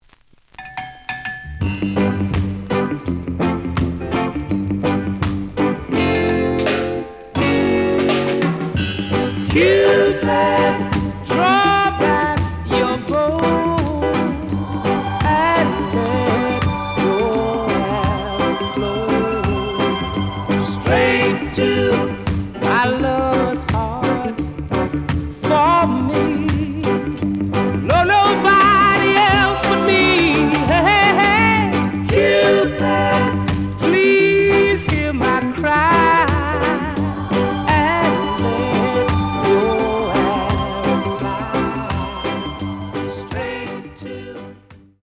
The audio sample is recorded from the actual item.
●Genre: Rock Steady